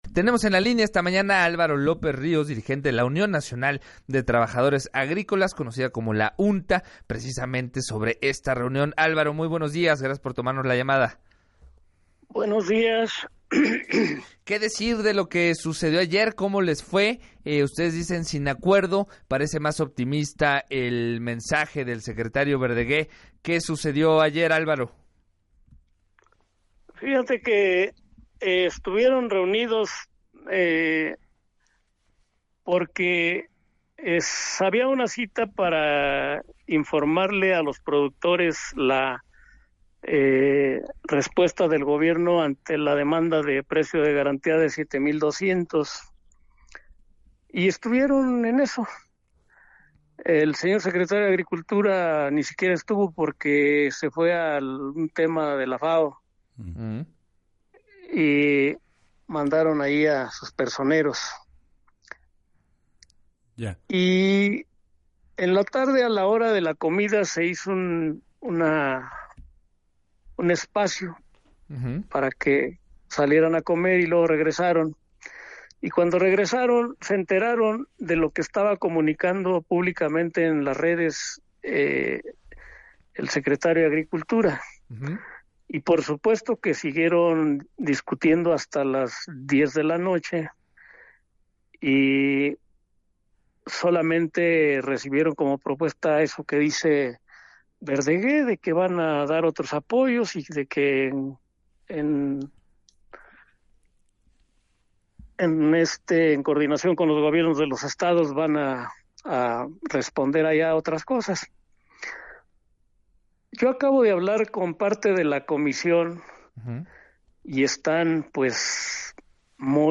En entrevista para “Así las Cosas”